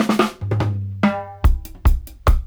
142-FILL-DRY.wav